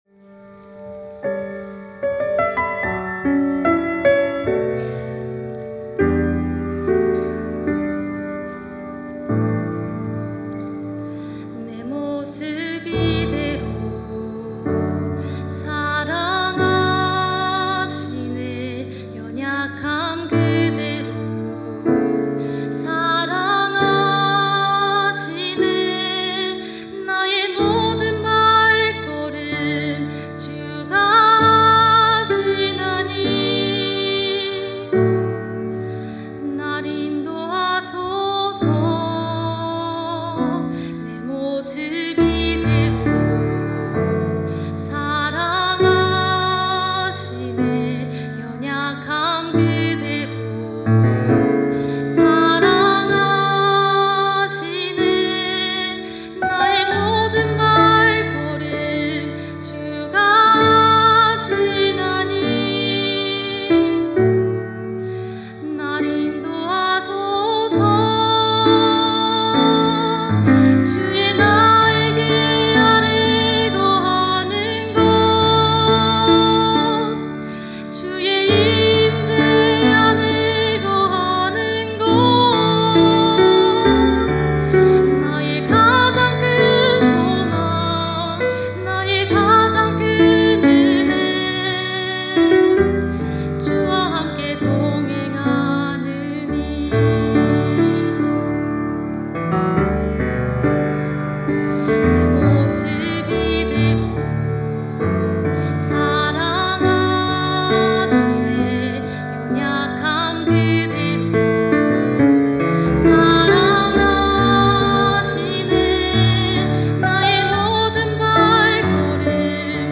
특송과 특주 - 내 모습 이대로